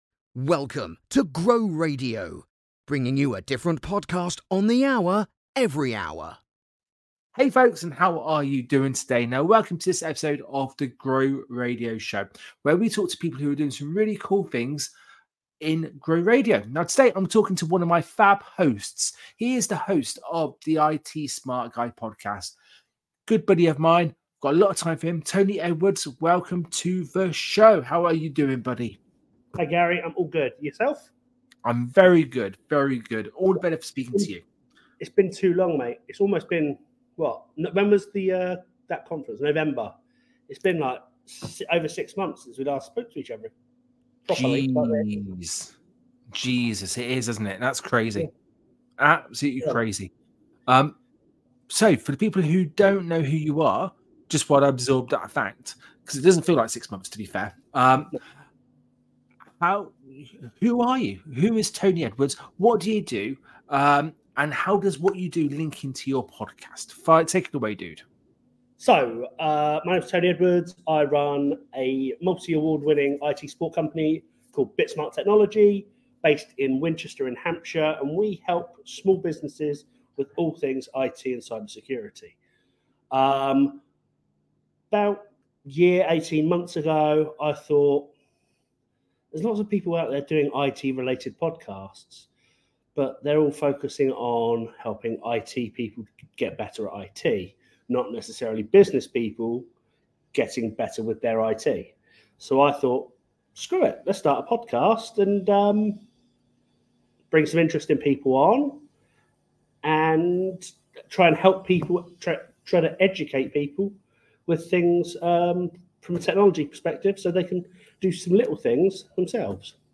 Welcome to the Tenth episode of The GROW Radio Show, your go-to podcast for insightful conversations with hosts, sponsors, event organisers, and community leaders associated with GROW Radio.